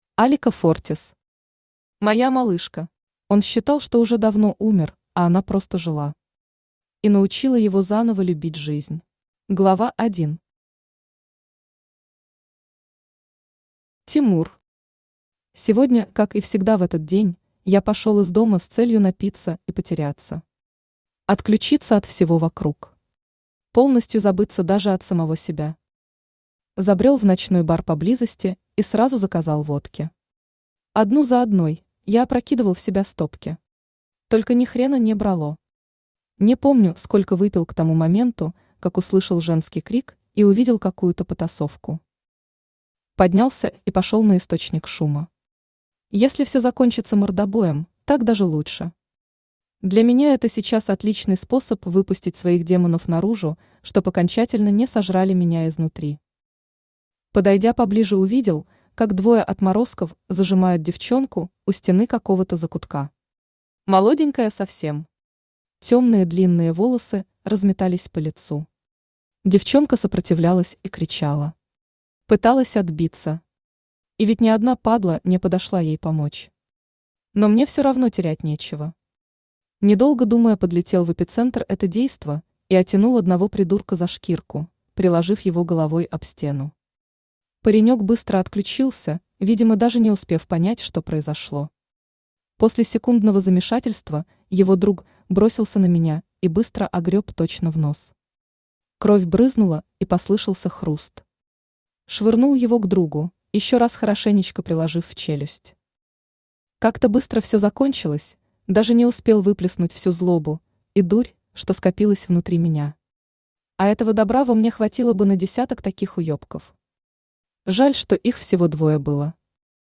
Aудиокнига Моя малышка Автор Алика Фортис Читает аудиокнигу Авточтец ЛитРес.